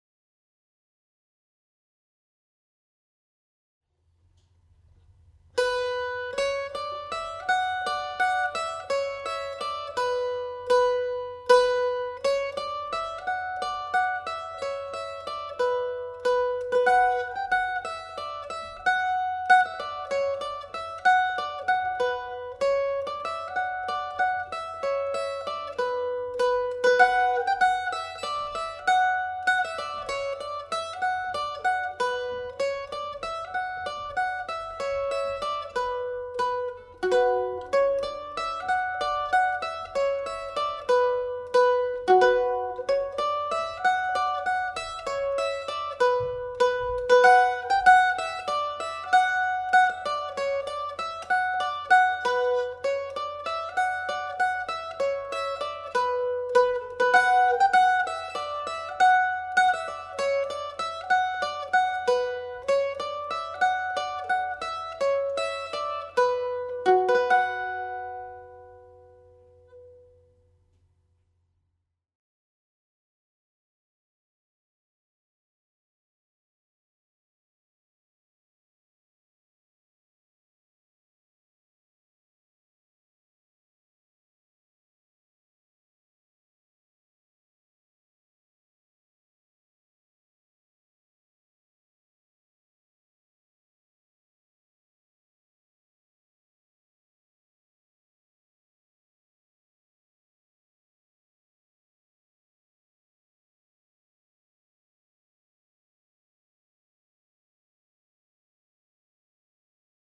• banjo scale
Breton Dance Tune (A Minor)
played at normal speed